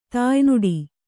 ♪ tāynuḍi